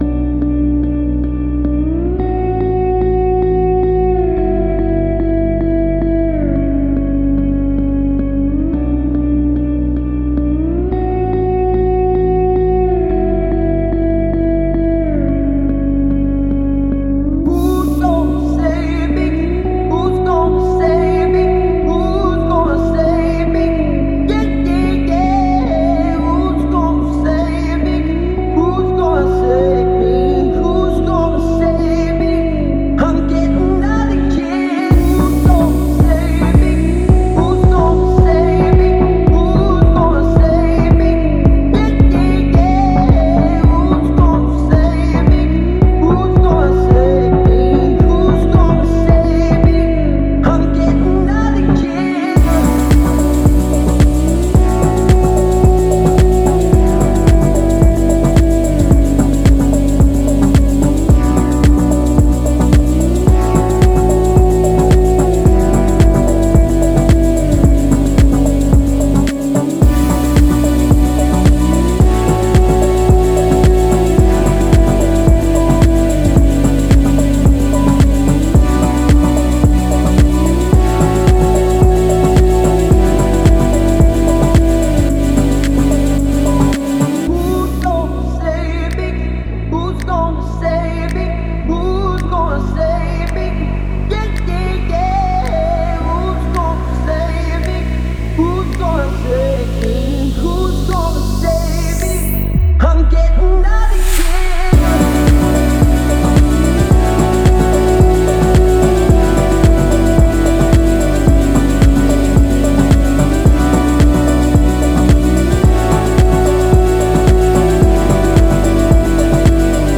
который сочетает в себе элементы поп и электронной музыки.